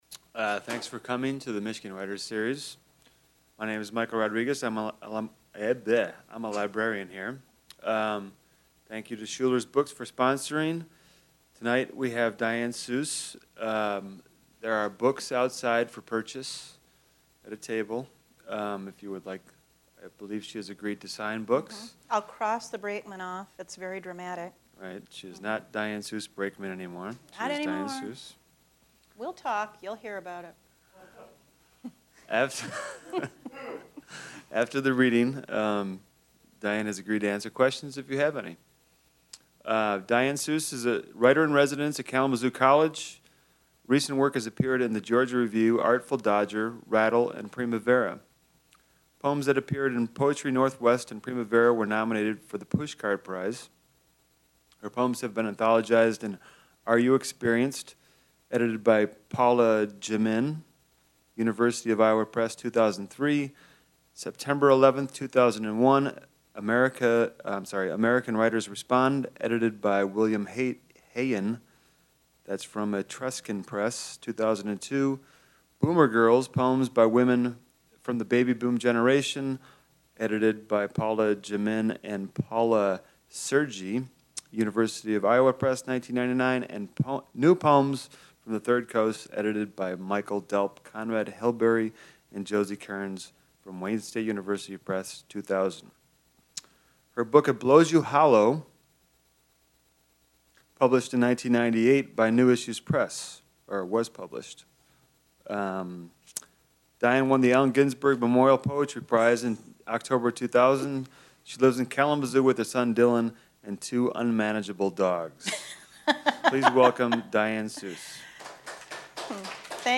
Poet Diane Seuss reads her selected works at the Michigan Writers Series